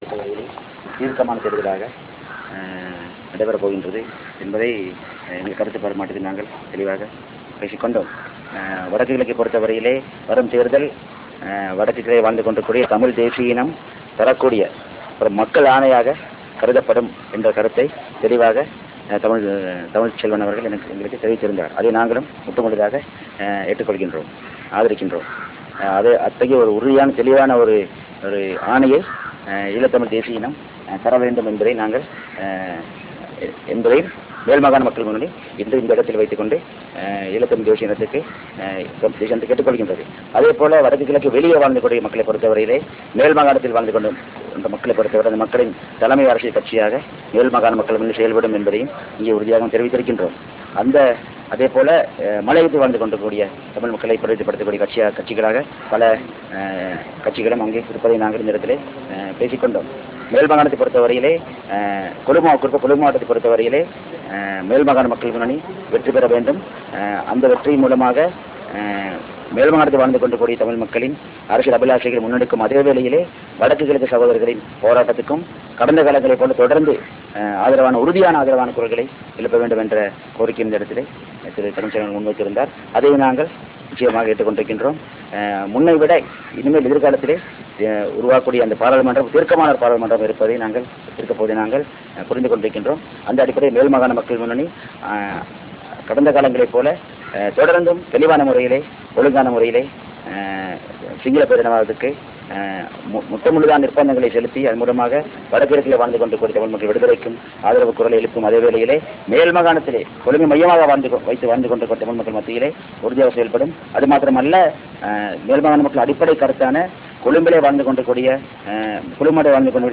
Real Audio IconMano Ganeshan speaks to reporters(Tamil)